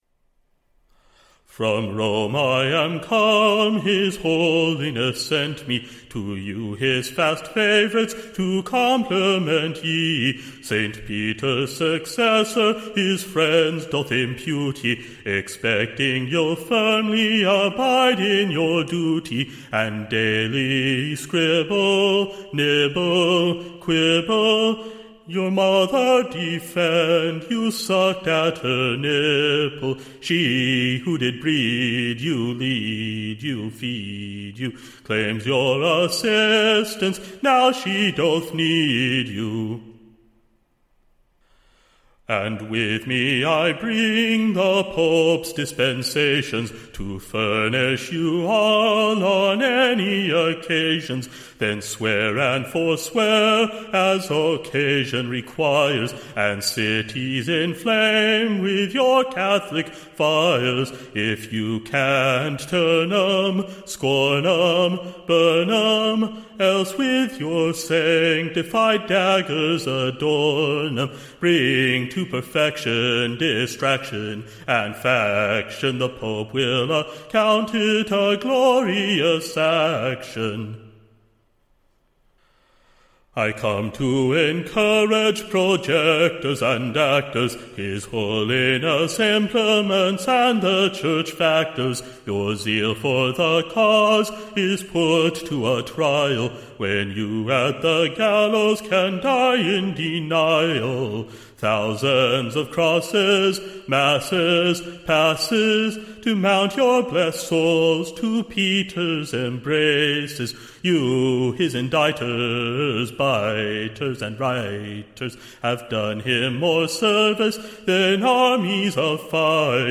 Recording Information Ballad Title A Message from Tory-Land / To the VVhig-Makers in Albian. Tune Imprint To the Tune of, Sawney and Jockey.